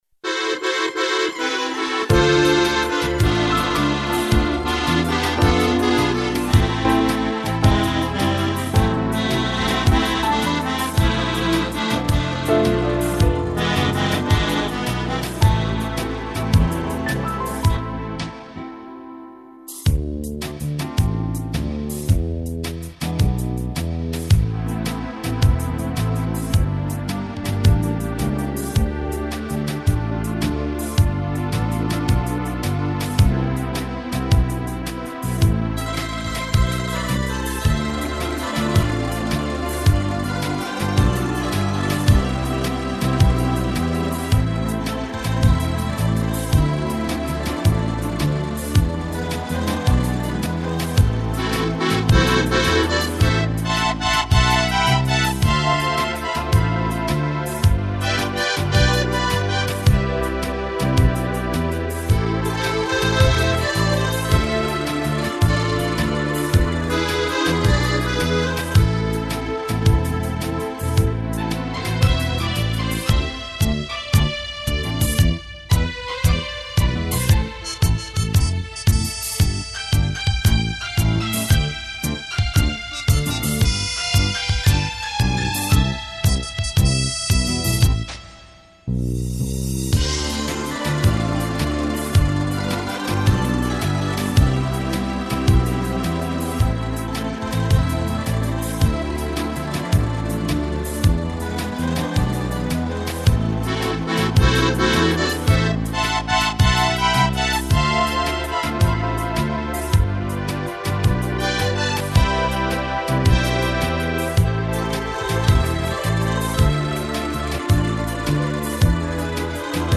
минус (фонограмма, музыкальное сопровождение) к песне